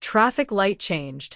traffic_sign_changed.wav